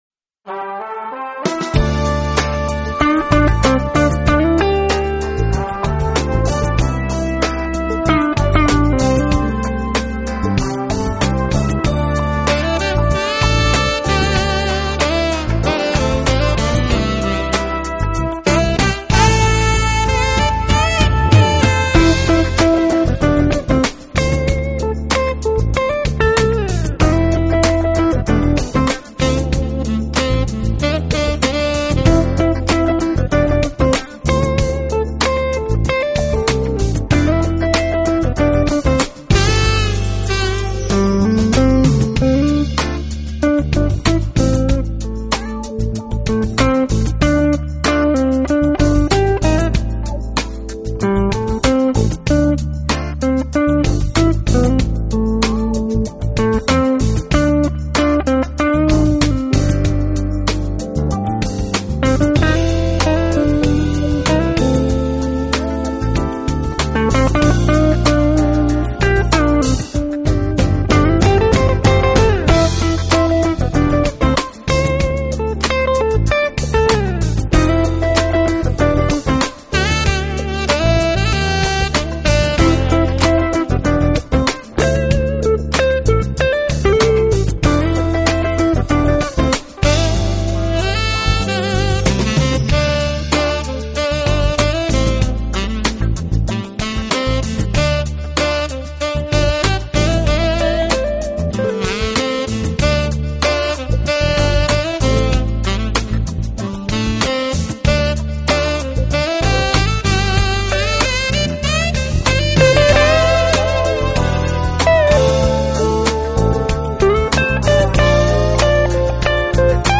【爵士吉他】
音乐类型：Smooth Jazz
tenor saxophone